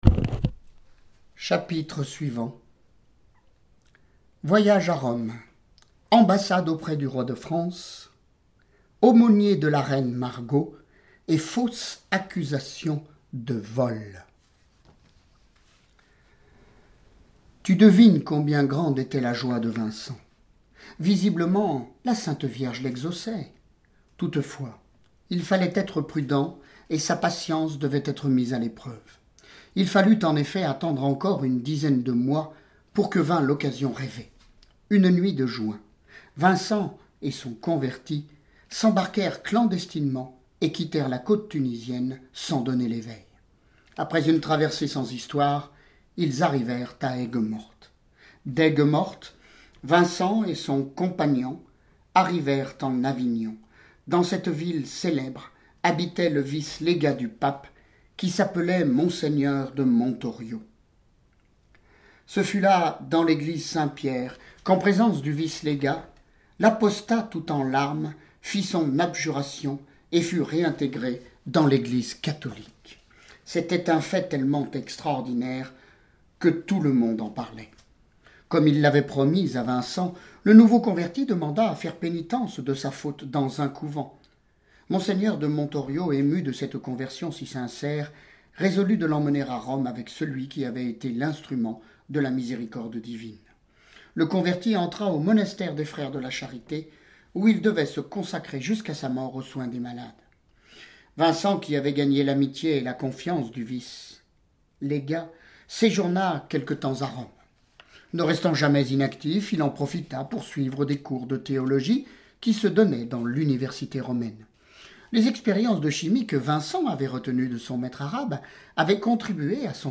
Lecture de vie de Saints et Saintes >> Saint Vincent de Paul